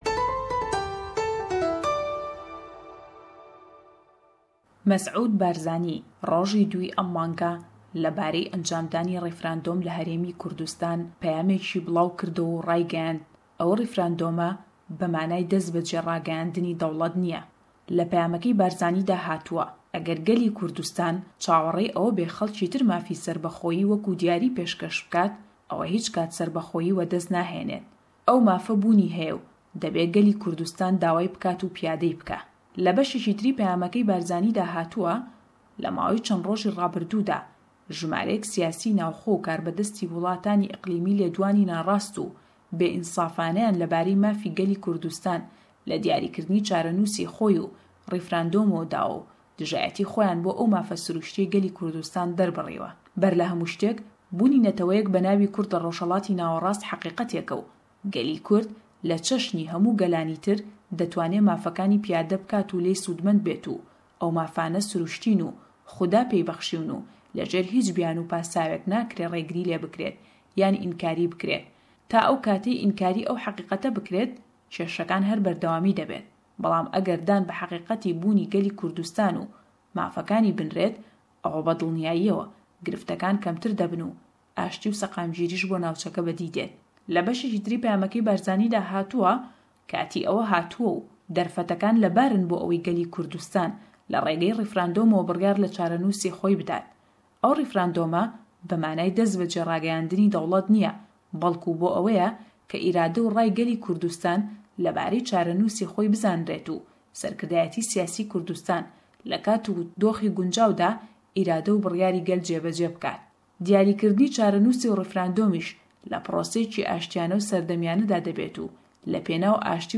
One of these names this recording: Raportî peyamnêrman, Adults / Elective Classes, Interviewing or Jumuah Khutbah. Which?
Raportî peyamnêrman